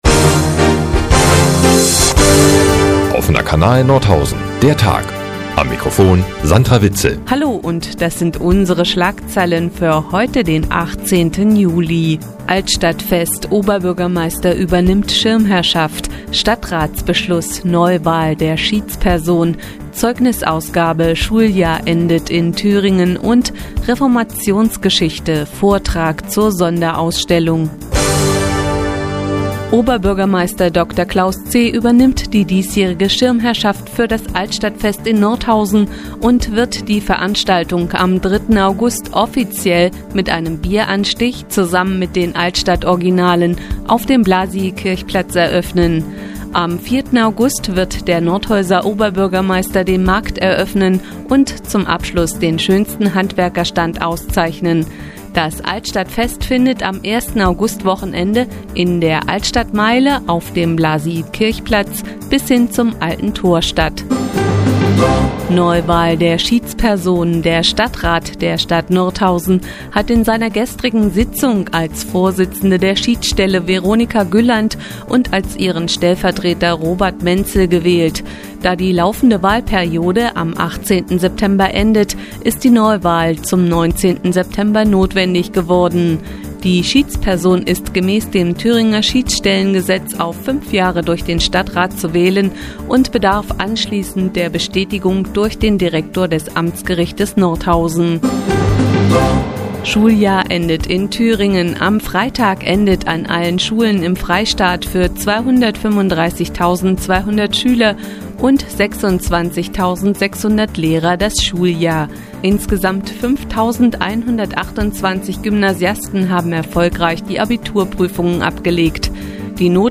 Mi, 15:30 Uhr 18.07.2012 „Der Tag auf die Ohren“ Seit Jahren kooperieren die nnz und der Offene Kanal Nordhausen. Die tägliche Nachrichtensendung des OKN ist jetzt hier zu hören.